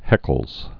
(hĕkəlz)